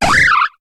Cri de Sapereau dans Pokémon HOME.